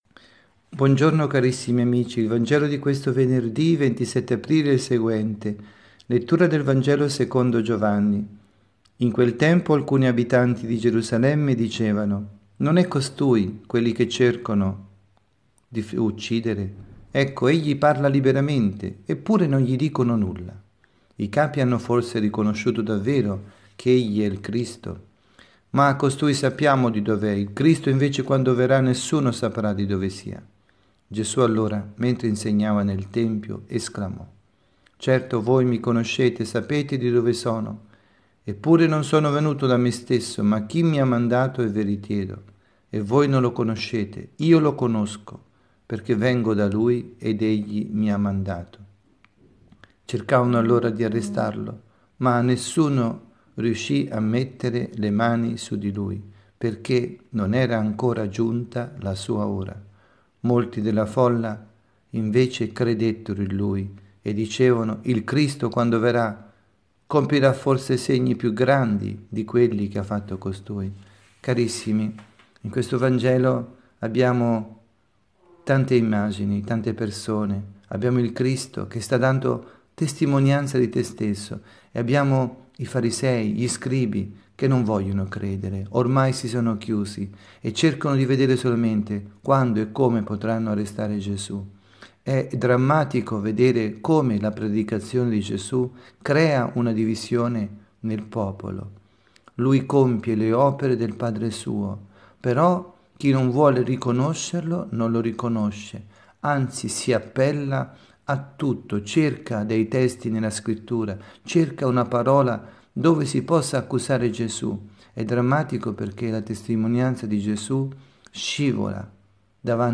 Omelia
dalla Casa di Riposo S. Marta – Milano